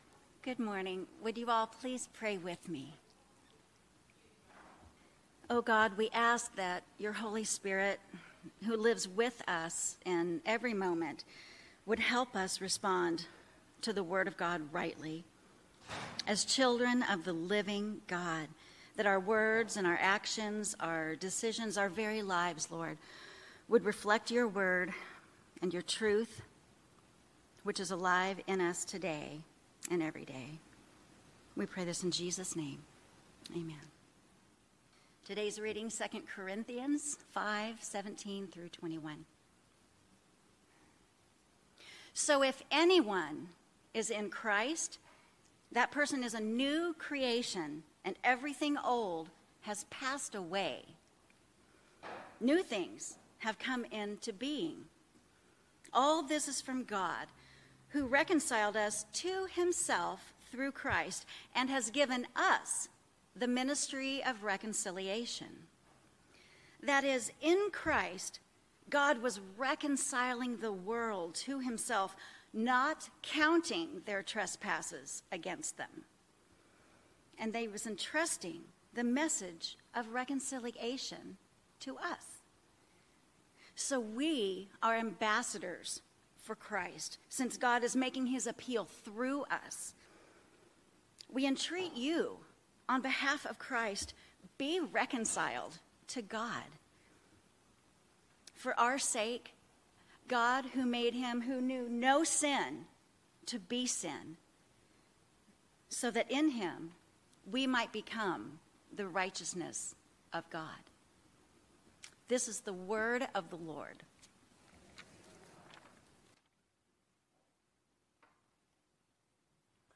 Knox Pasadena Sermons